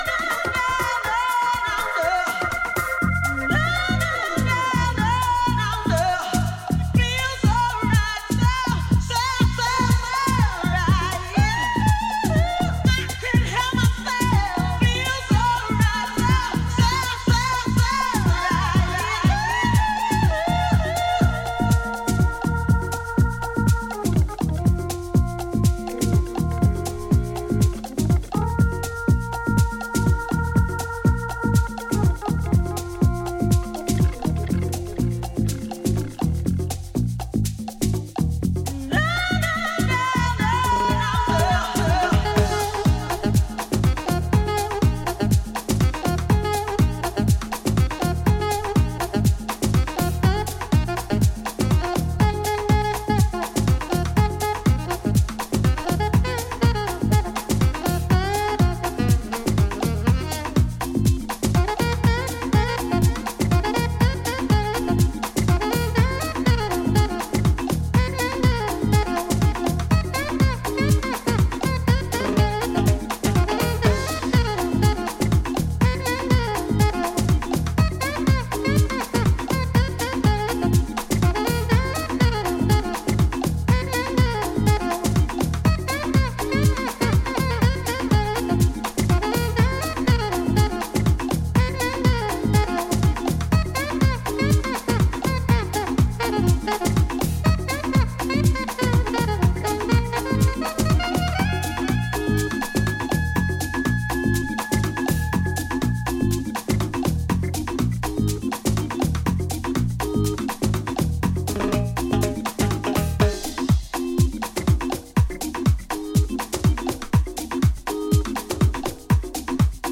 luxuriously jazzy version
remixed